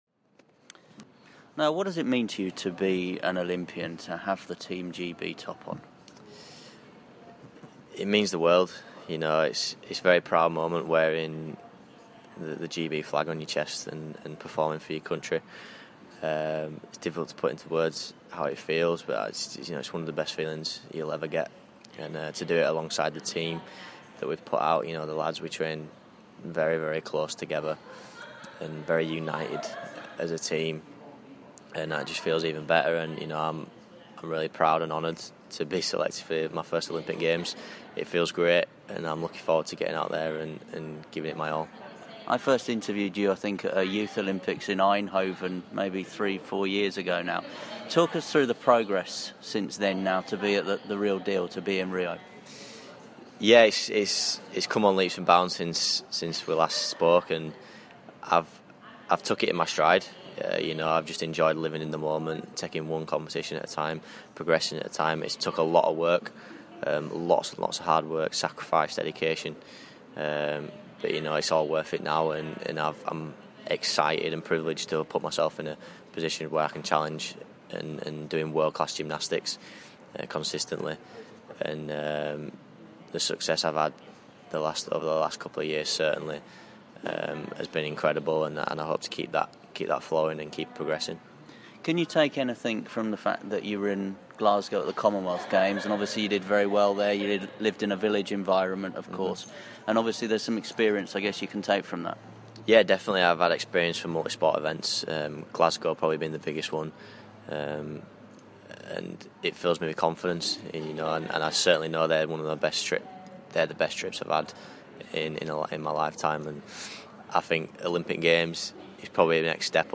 Leeds gymnast Nile Wilson speaks to Radio Yorkshire